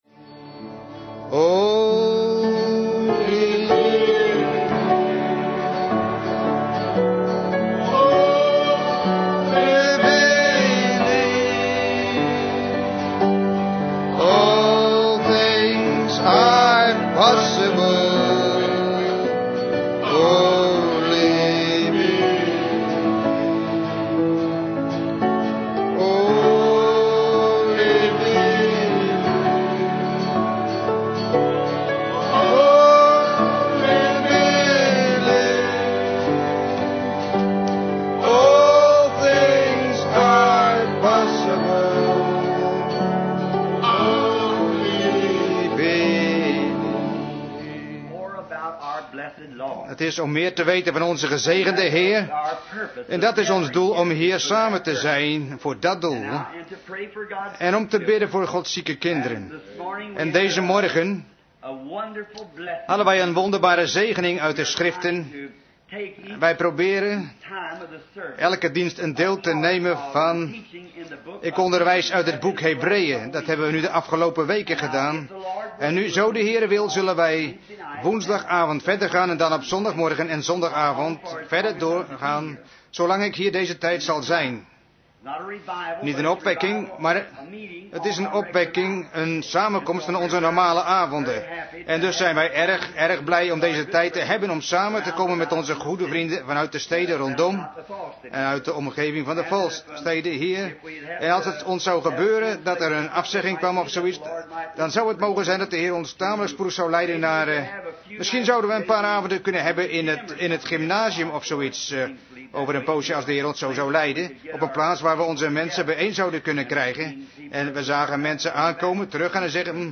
Vertaalde prediking "Hebrews, chapter four" door W.M. Branham te Branham Tabernacle, Jeffersonville, Indiana, USA, 's avonds op zondag 01 september 1957